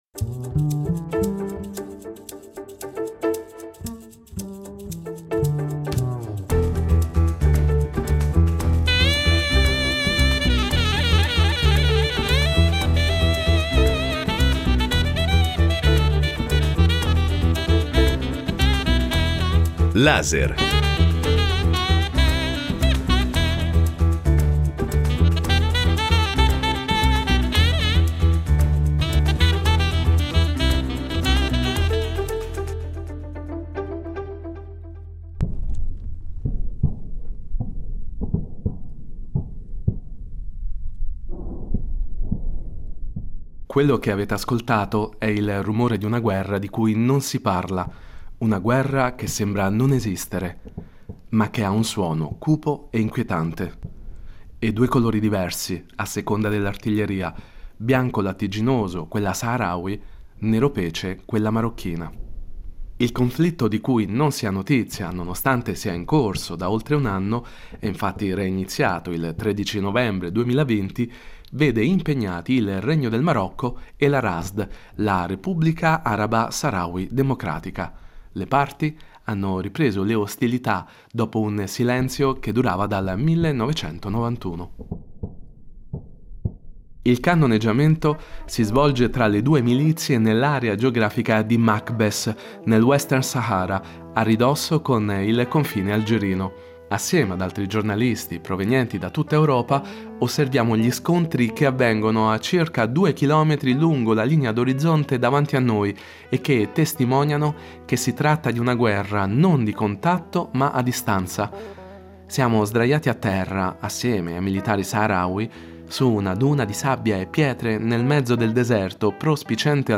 Un reportage dal fronte di guerra che racconta, oltre al disequilibrio degli armamenti militari a favore delle truppe di Rabat, gli esiti sulle circa duecentomila persone che il Polisario rappresenta, ovvero gli esuli che vivono da più di quarant'anni nei campi profughi nei pressi di Tindouf, in territorio algerino.